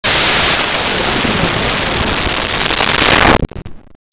Alien Woosh 02
Alien Woosh 02.wav